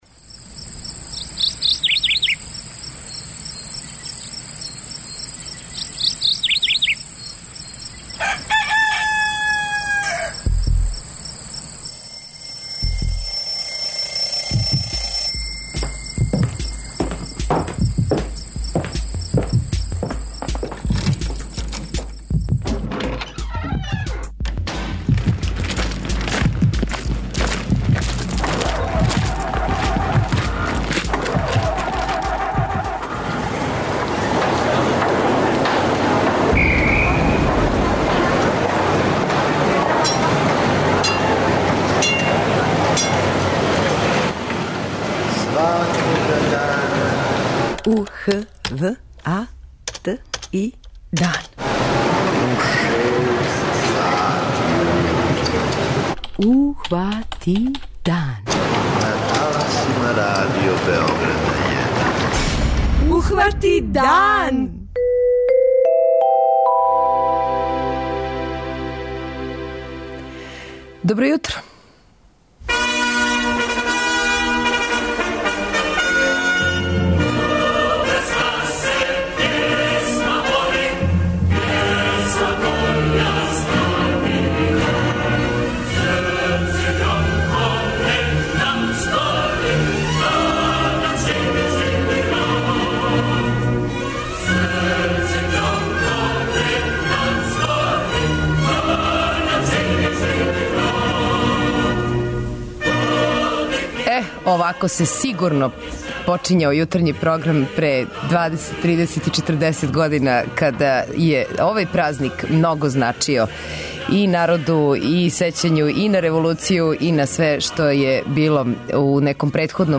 На Међународни празник рада, већи део емисије биће посвећен начинима обележавања овог дана. Од наших дописника сазнаћемо како су изгледали првомајски уранци у Чачку, Ужицу, Зрењанину и Нишу.
преузми : 85.94 MB Ухвати дан Autor: Група аутора Јутарњи програм Радио Београда 1!